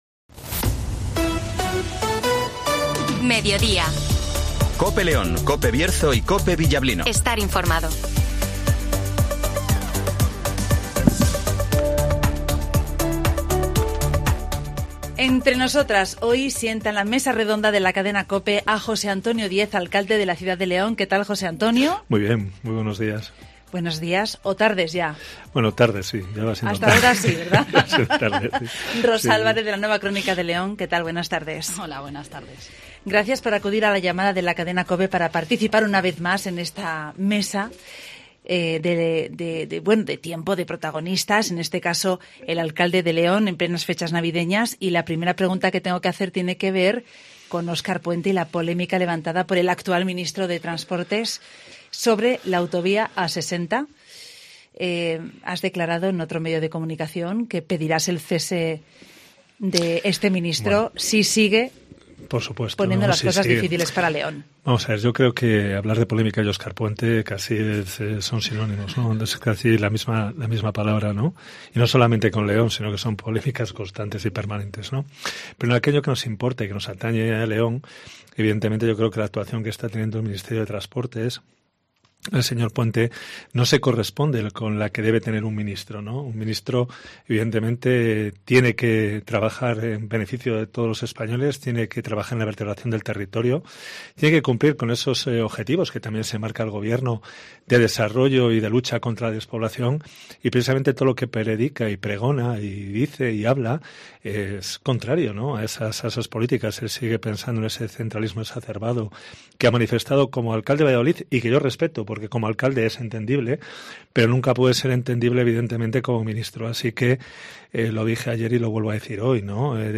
El Alcalde de León, Jose Antonio Diez, se sienta a la mesa de la Cadena COPE para participar en el espacio "Entre Nosotras".